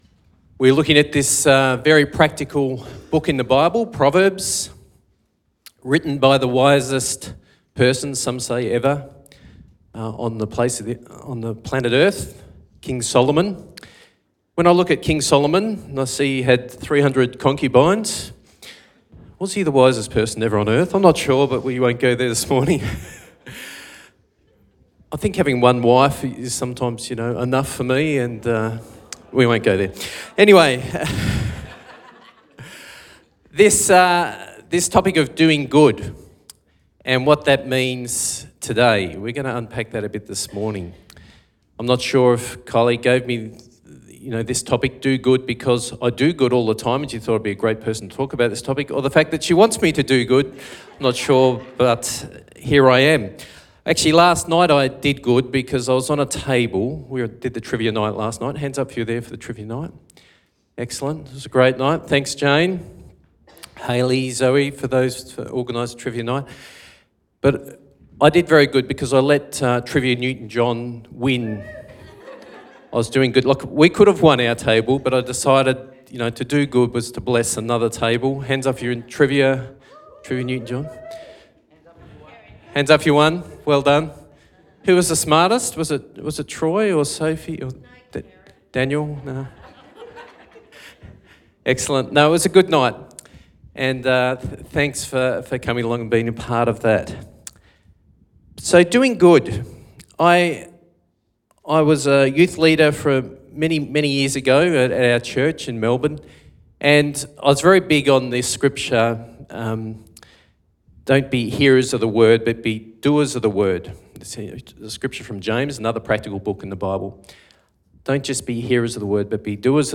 Sermon Podcasts